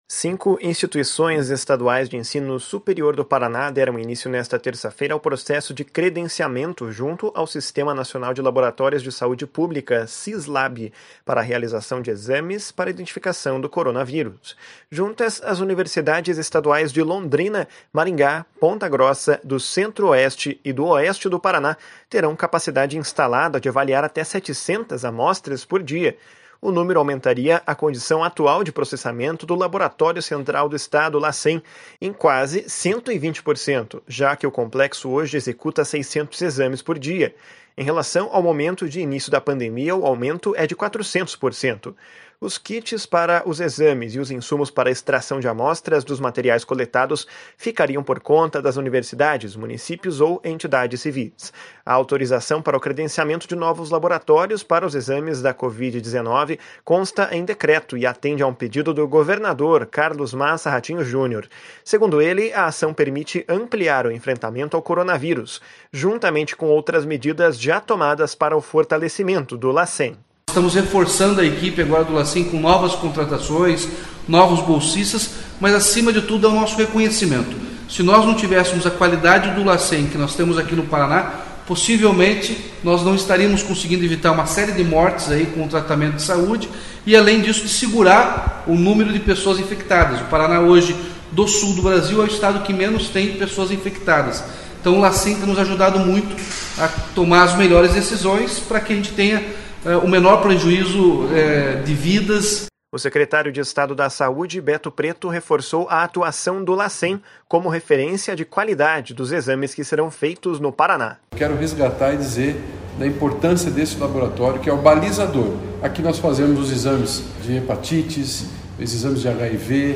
// SONORA RATINHO JUNIOR //
// SONORA ALDO BONA //